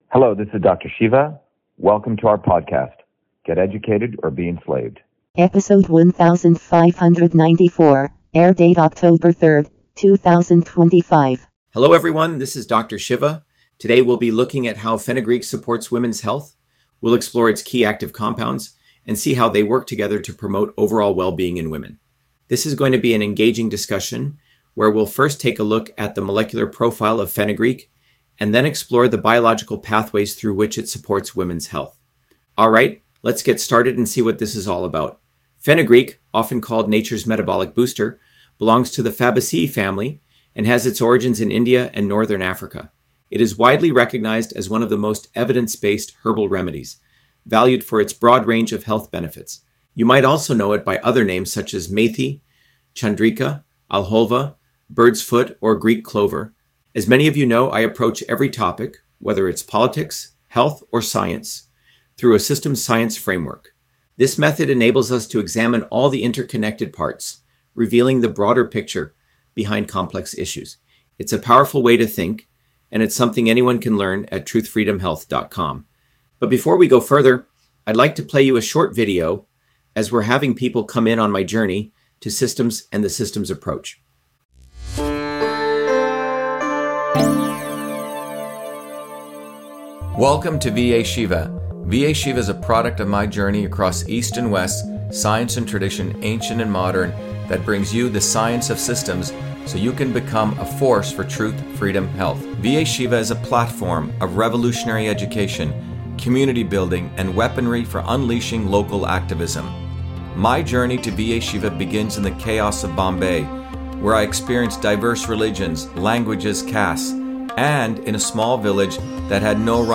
In this interview, Dr.SHIVA Ayyadurai, MIT PhD, Inventor of Email, Scientist, Engineer and Candidate for President, Talks about Fenugreek on Women’s Health: A Whole Systems Approach